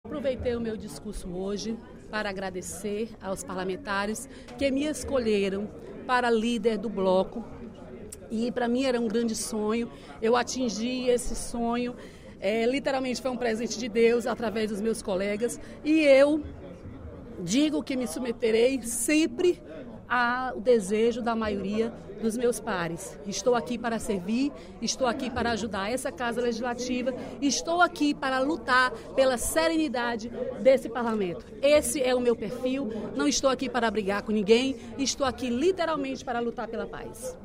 A deputada Dra. Silvana (PMDB) falou, durante o primeiro expediente da sessão plenária desta terça-feira (13/06), sobre a escolha dela como líder do bloco PMDB-PSD-PMB na Assembleia Legislativa.